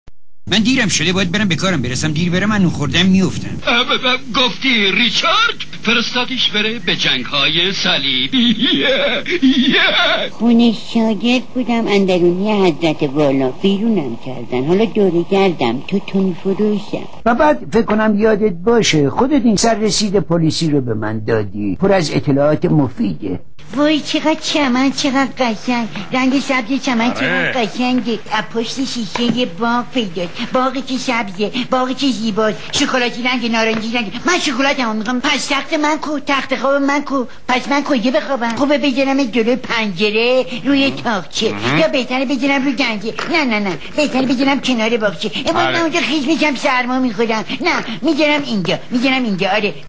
زنده یاد اصغر افضلی از حنجره های طلایی های دنیای دوبله ایران محسوب می شود.
ویژگی صدای او تیپ‌سازی‌های متفاوت کمدی در فیلم‌ها و کارتون‌ها است.